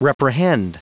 Prononciation du mot reprehend en anglais (fichier audio)
Prononciation du mot : reprehend